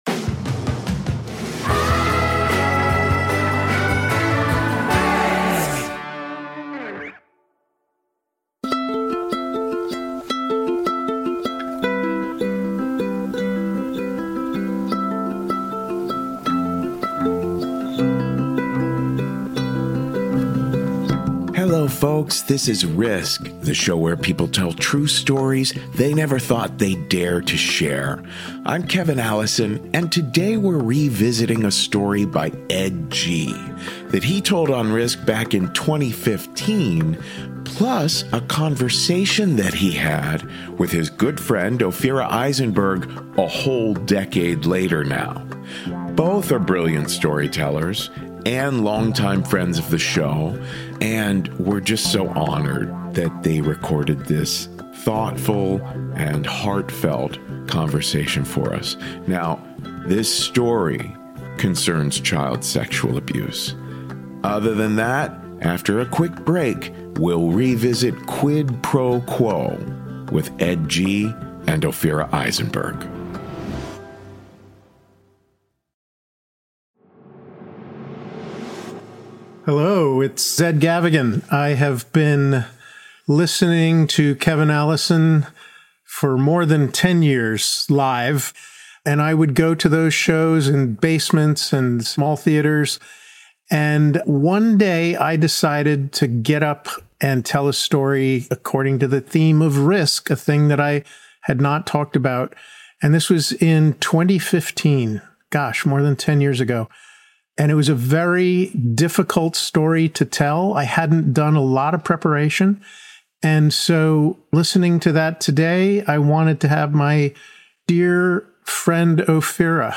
Now, ten years later, Ophira Eisenberg sits down with him to go places that story couldn't: the FBI, the Pope, the survivors who found each other decades later, and the hard-won question of where shame really belongs.